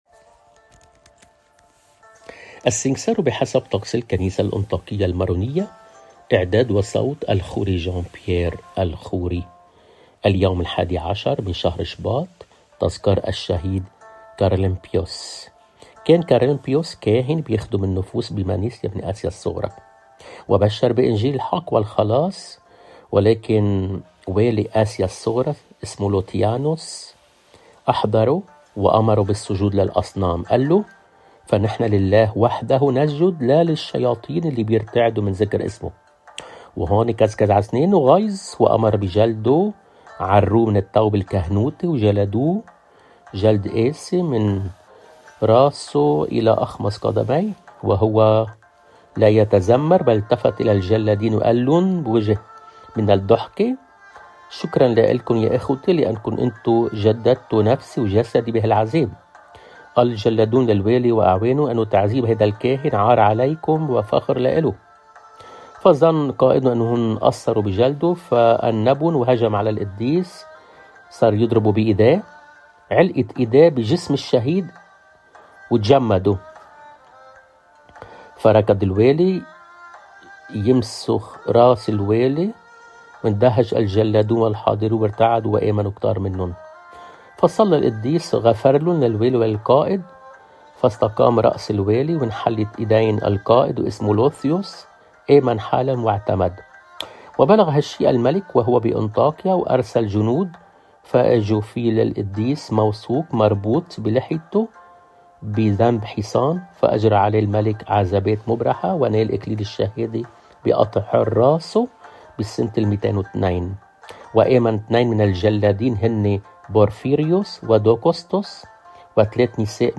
بصوت